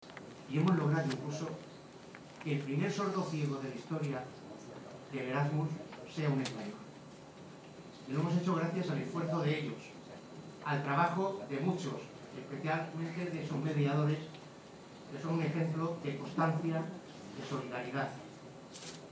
Acto en Murcia